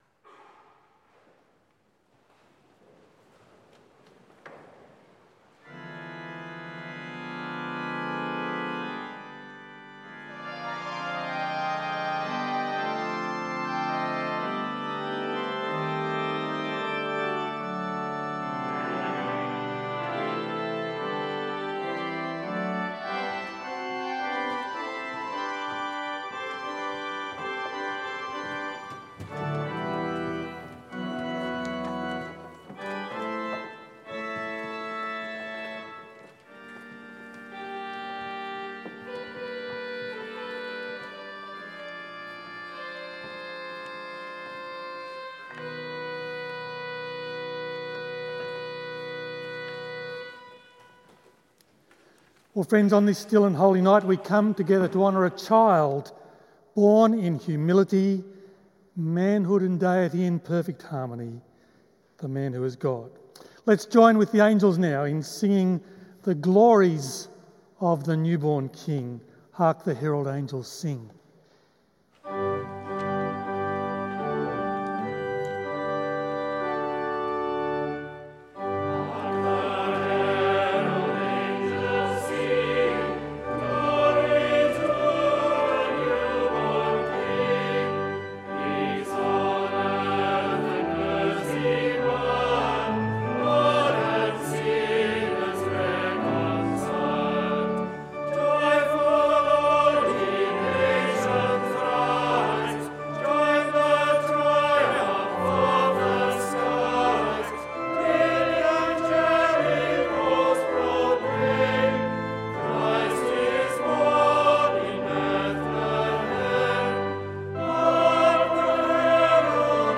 The Scots' Church Melbourne Christmas Eve Service 2021
Full Service Audio